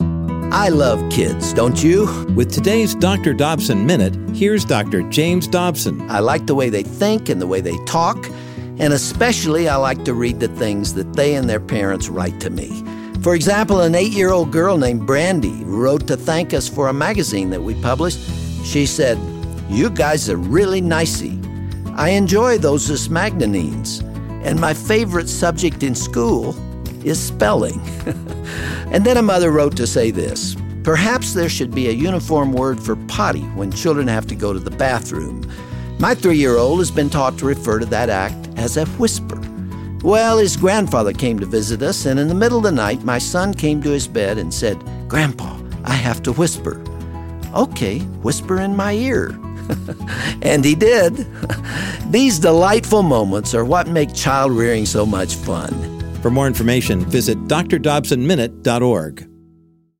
Dr. Dobson shares some humorous stories about children that he received through his ministry.